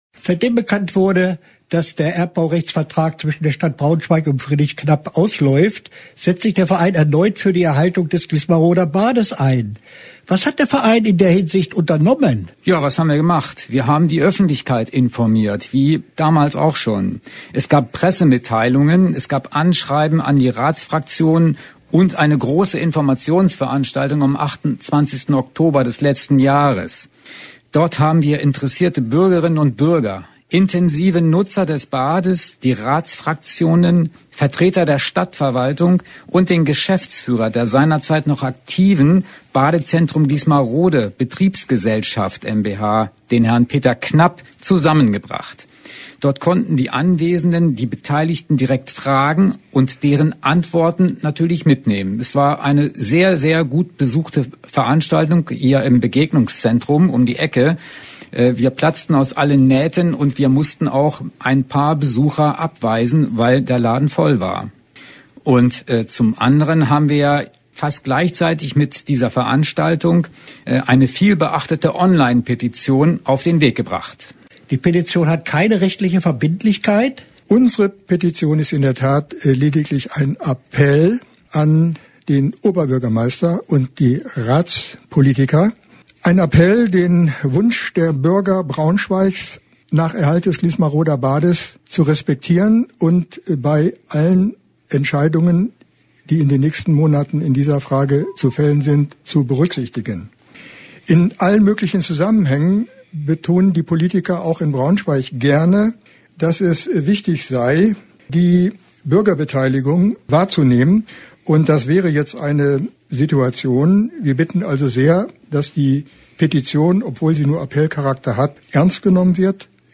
Interview-Foerderverein-Gliesmaroder-Bad.mp3